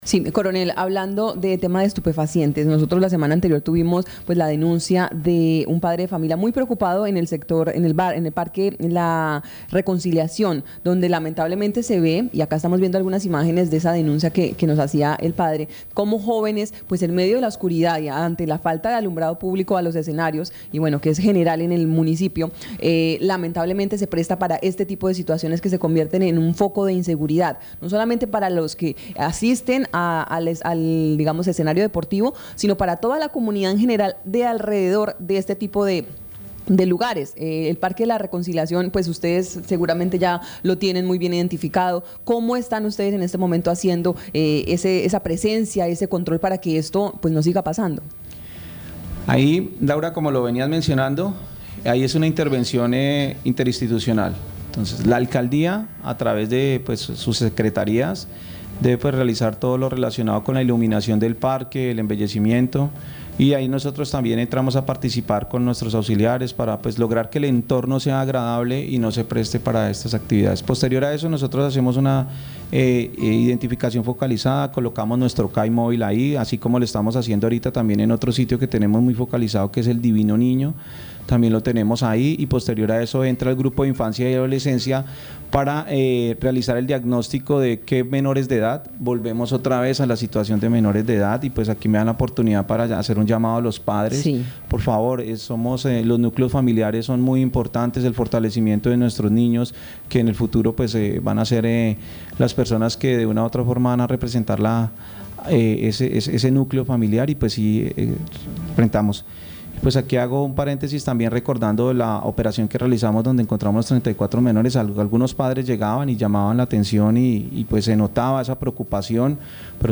Escuche a coronel Ángel Alexander Galvis Ballén, comandante Departamento Policía Guaviare